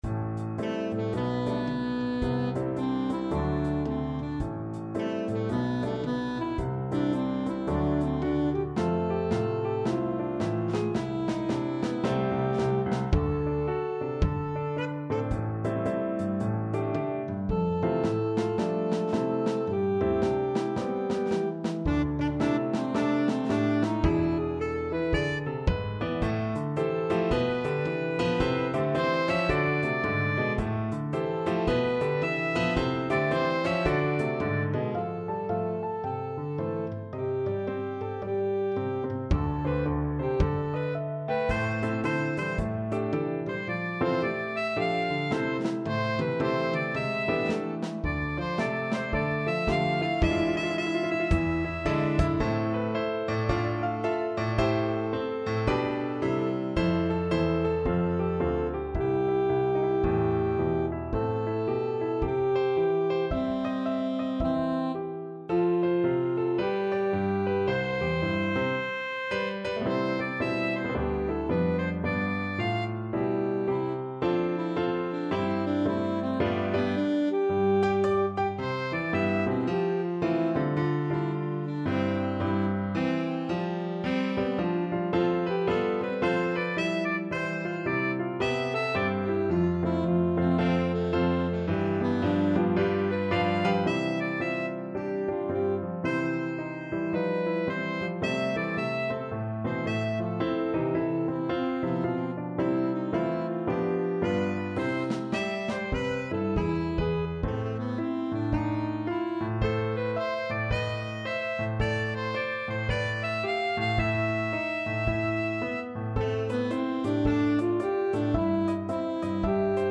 Classical Jazz